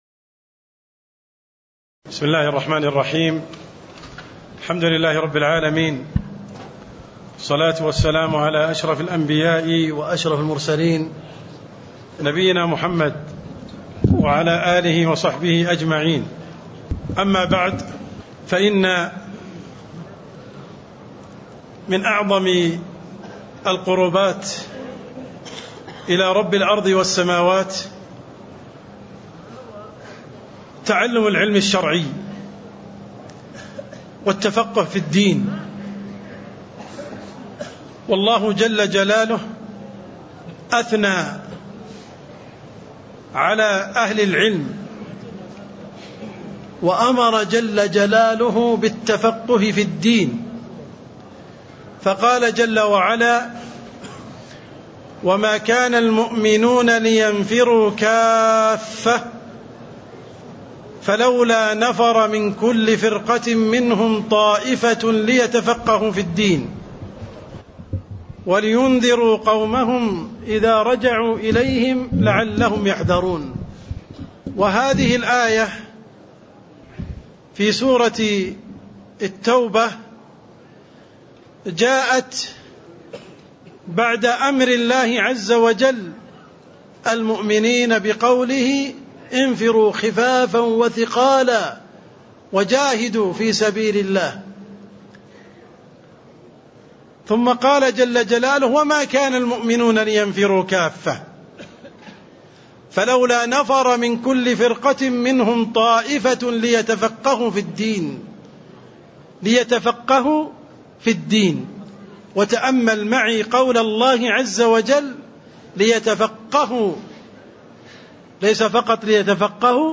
تاريخ النشر ١١ ربيع الثاني ١٤٣٥ هـ المكان: المسجد النبوي الشيخ